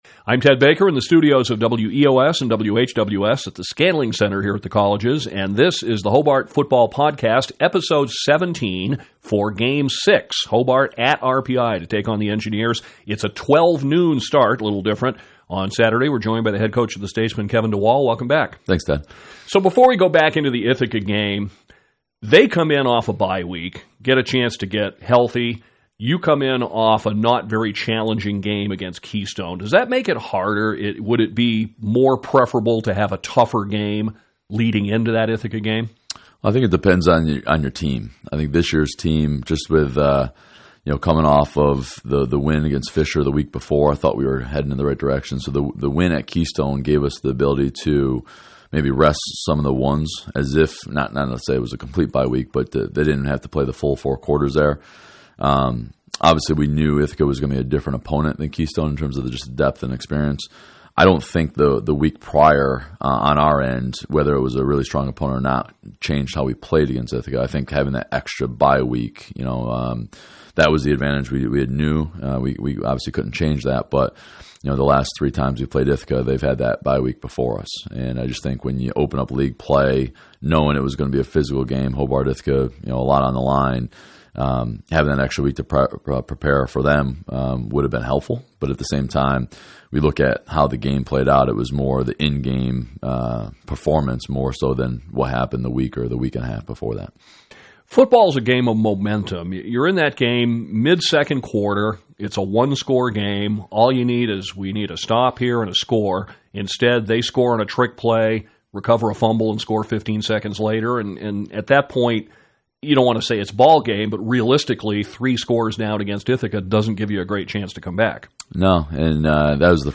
back in the studio